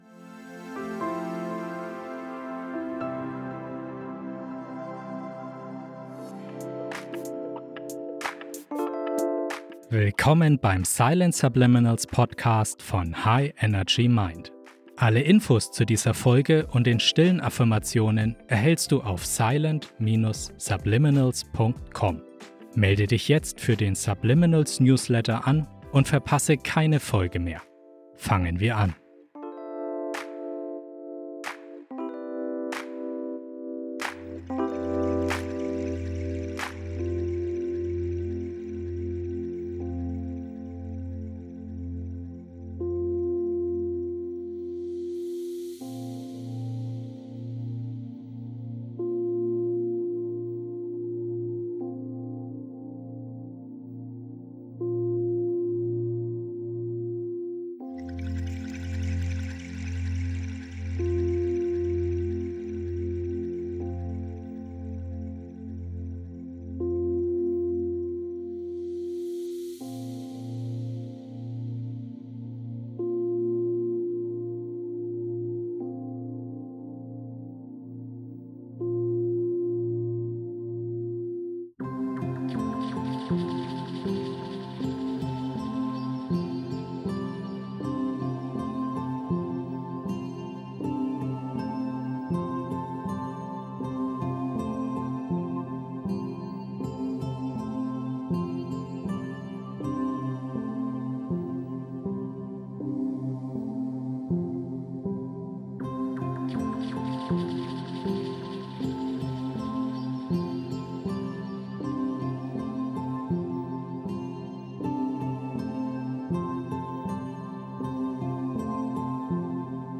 Dann ist diese Folge des Silent Subliminal Podcasts genau das Richtige für dich!Tauche ein in eine Welt der Entspannung und geistigen Erneuerung, während dich sanfte 432 Hz-Musik in meditative Zustände entführt.